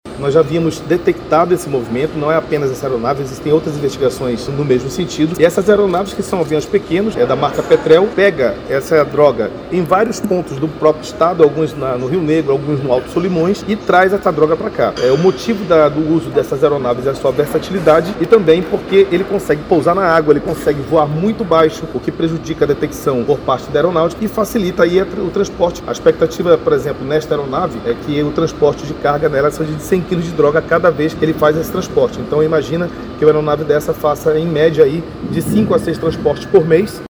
SONORA01_VINICIUS-ALMEIDA.mp3